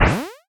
springboard.ogg